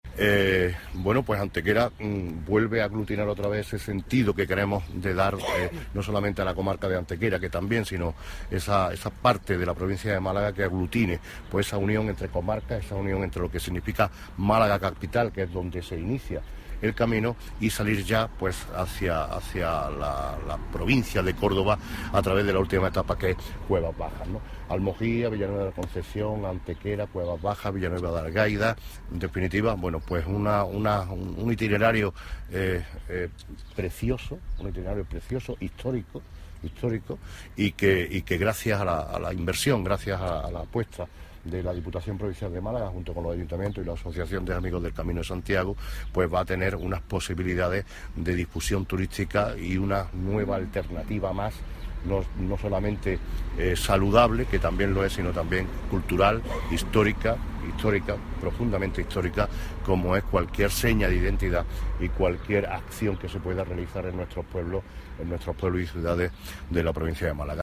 Cortes de voz
Manuel Barón, alcalde de Antequera, destaca el carácter histórico y cultural de la ruta   1056.78 kb  Formato:  mp3